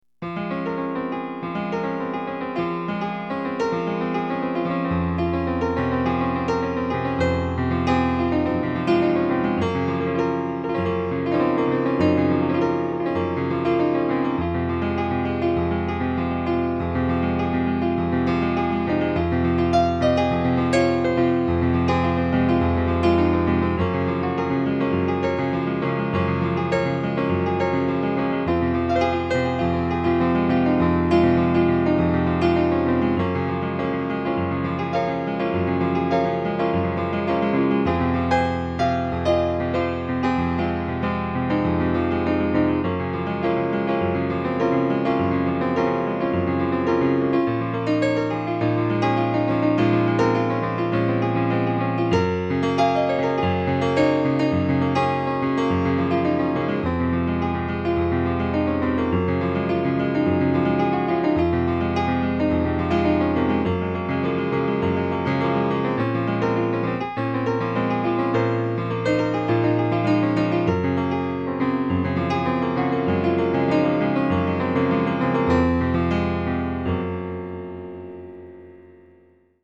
These improvisations are just musical ideas and expressions all created in the moment, a stream of consciousness. The keyboard used is a controller so the musical response quality is limited to the nature of the animal.